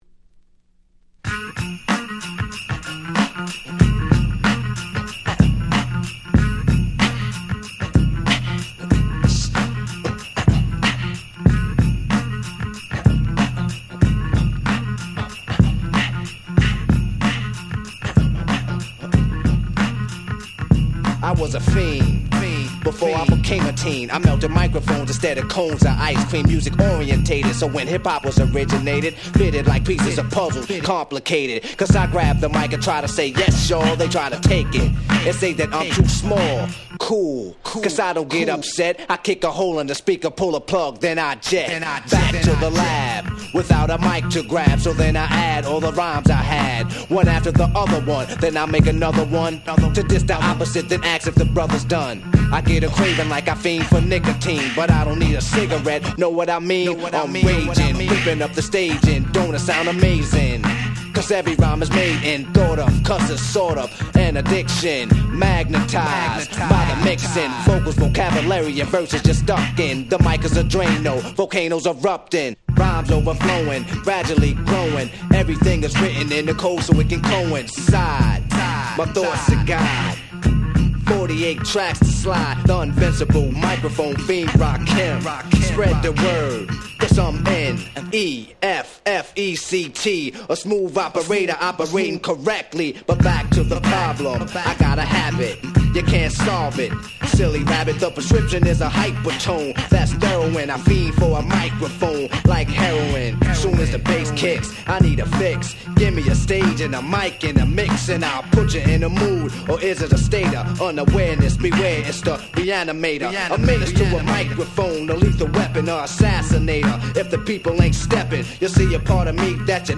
88' Super Hip Hop Classics !!
問答無用の80's Hip Hop Classics !!